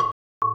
Listen to first part of A2 bar followed by synthetic A2 bar